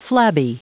Prononciation du mot flabby en anglais (fichier audio)
Prononciation du mot : flabby